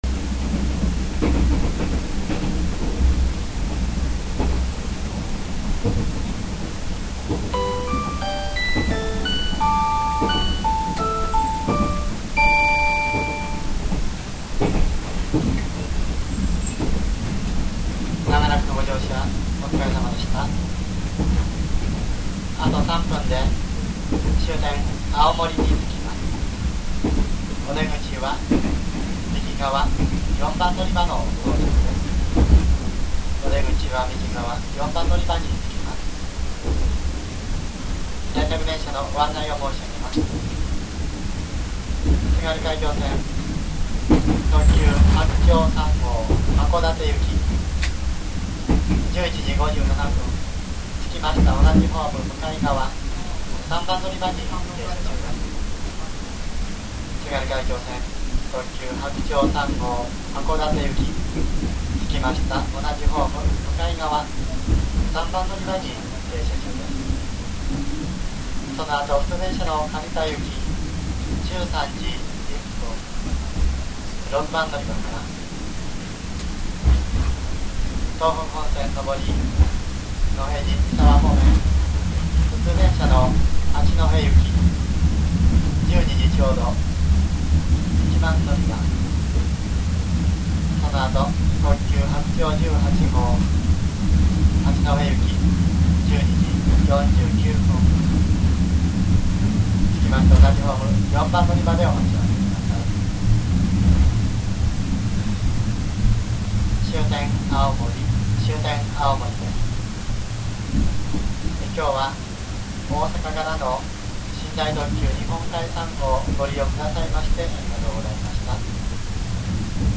これは青森到着時。